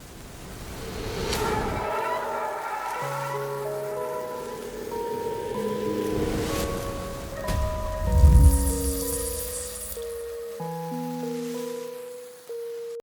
Рэп, Новинки